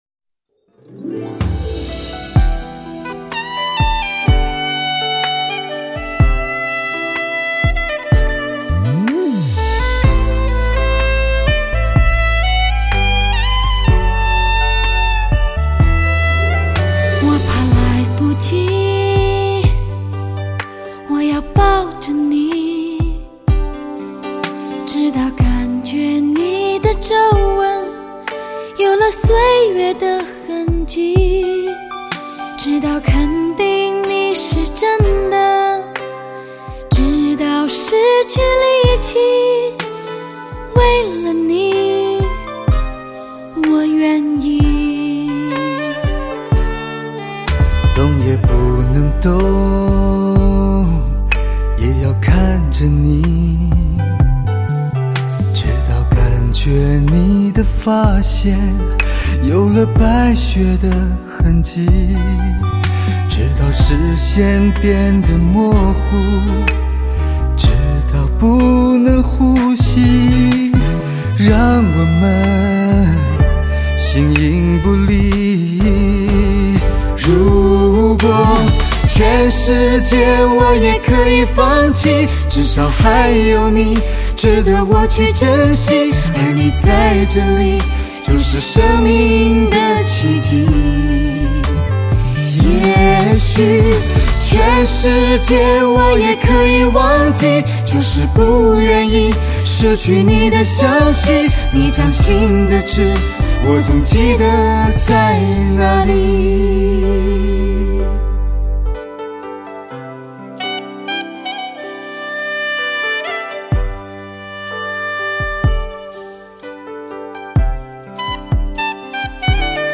，醇味发烧男女对唱全新演绎